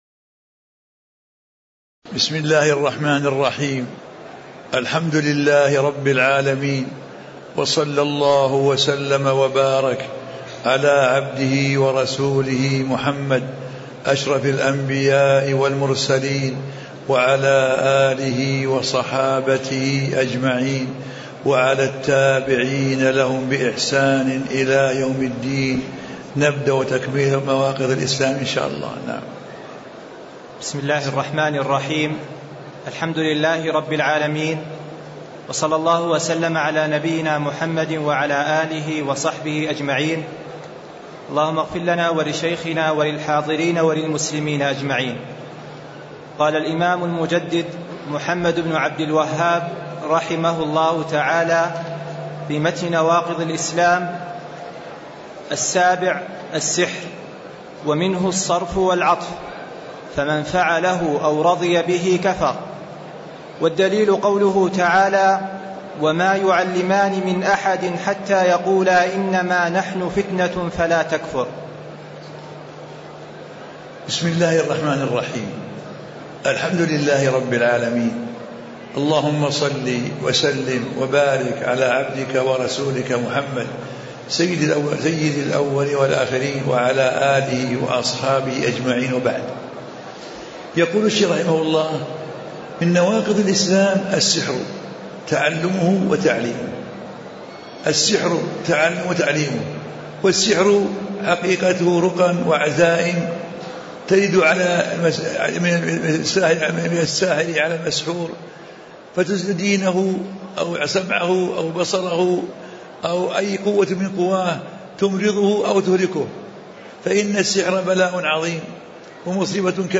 تاريخ النشر ٨ صفر ١٤٣٧ المكان: المسجد النبوي الشيخ: سماحة المفتي الشيخ عبدالعزيز بن عبدالله آل الشيخ سماحة المفتي الشيخ عبدالعزيز بن عبدالله آل الشيخ 002الناقض السابع السحر